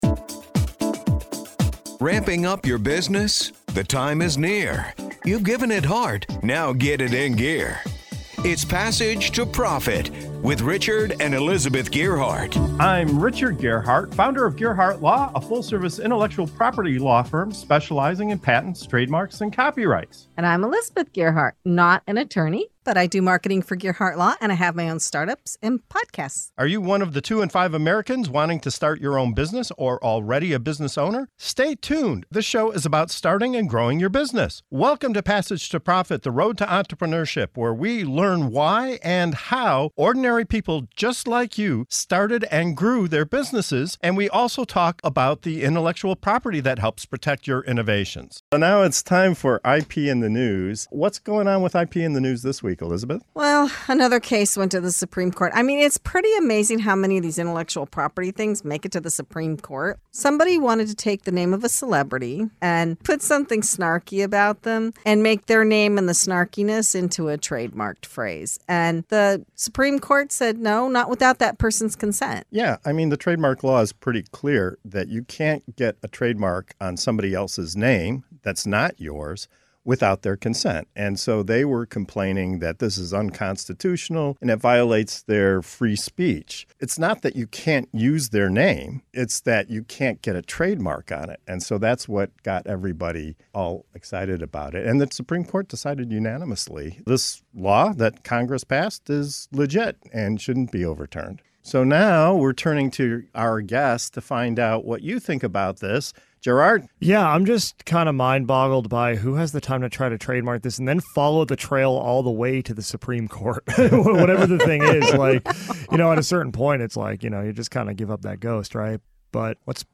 In this segment of “IP in the News”, we dive into a heated Supreme Court case that challenges the boundaries of trademark law and free speech. When someone attempted to trademark a snarky phrase using a celebrity's name, the court had the final say—no consent, no trademark.